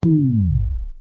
low_battery.ogg